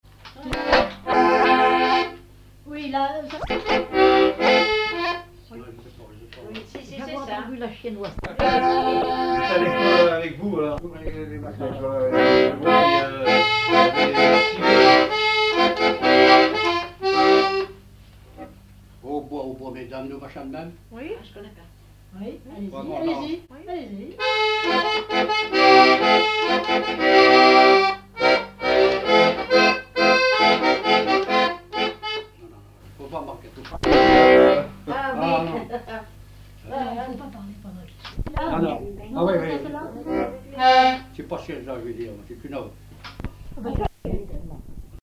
Catégorie Pièce musicale inédite